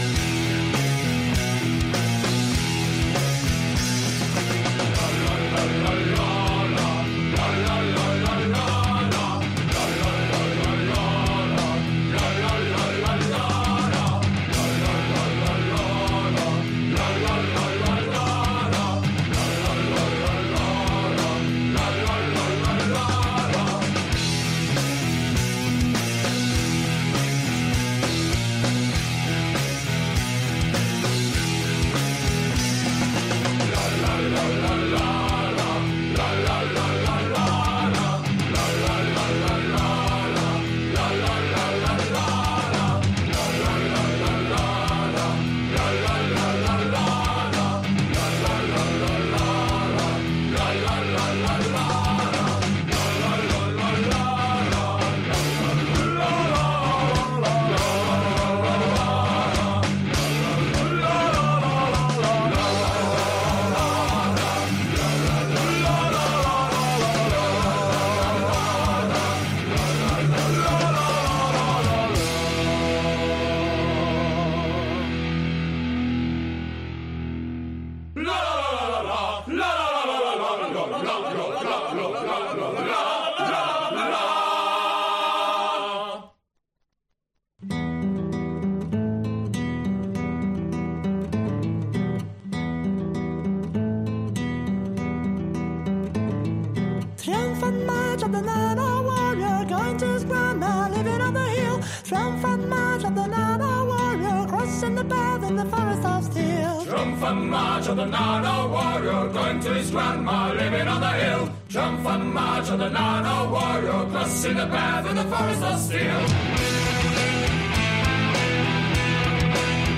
Celtic Folk Metal